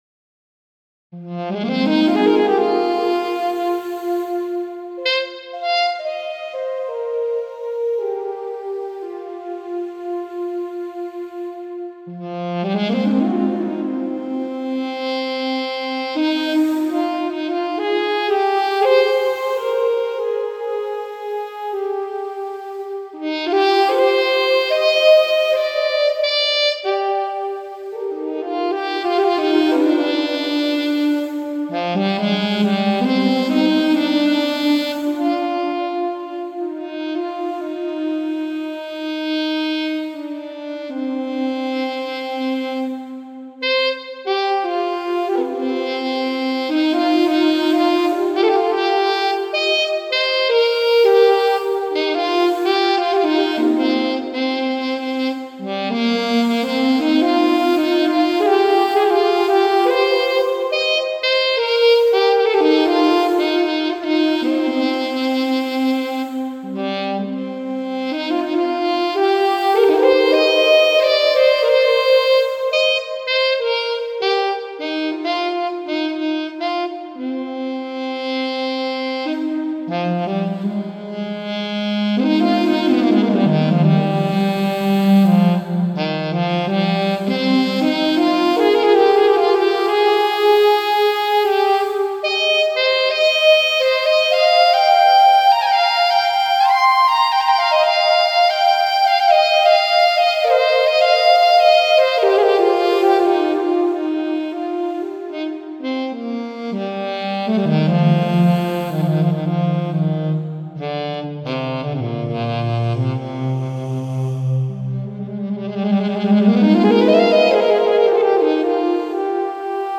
A few samples of my brain's creation (highly flawed, no corrections, taken as it comes) are below.
I also use solo SWAM instruments from Audio Modeling . Adding pads from Omnisphere creates nice backgrounds, and there are hundreds of pads and other voices to explore.